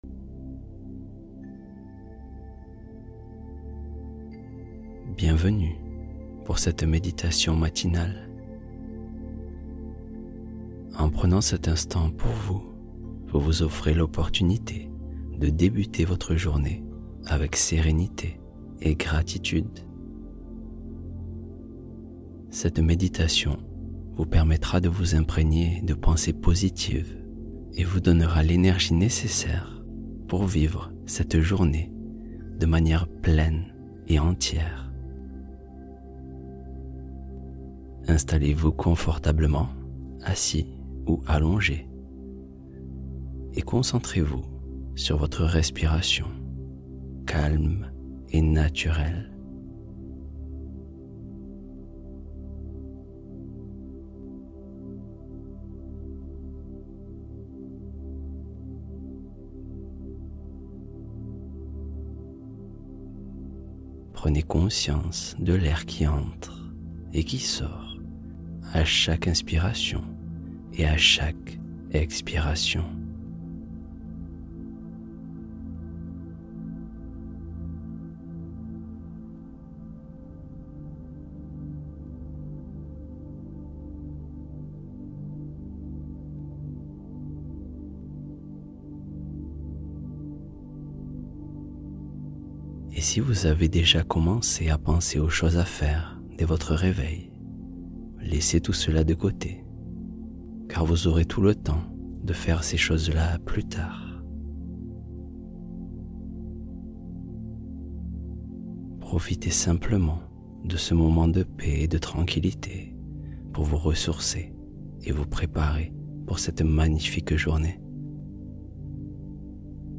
Réveillez-vous Rempli de GRATITUDE | La Méditation Matinale Qui Change Votre Vibration